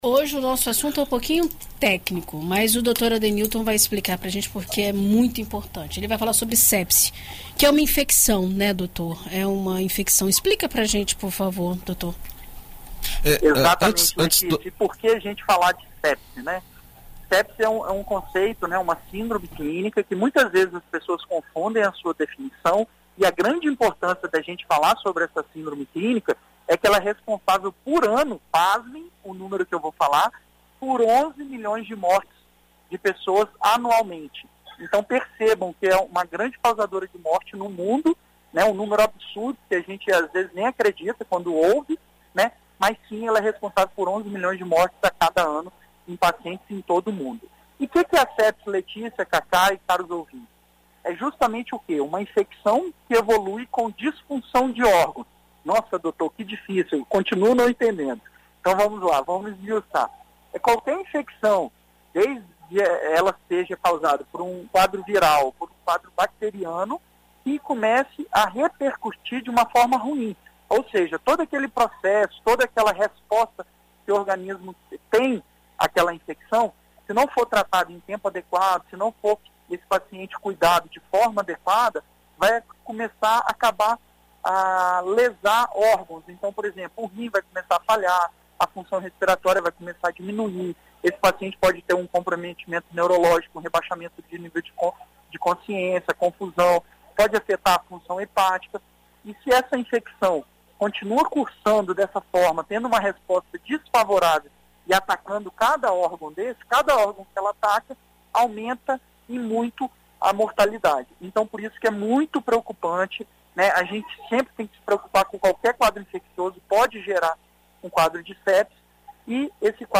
Na coluna Visita Médica desta quinta-feira (15), na BandNews FM Espírito Santo